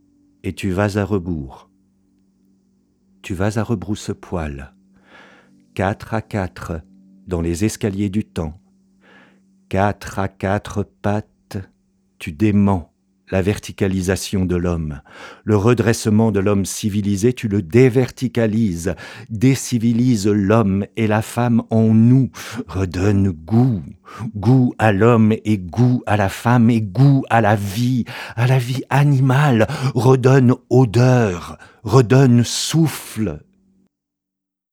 EXTRAITS SONORES L'Homme printemps
Les voix y entrent en résonance.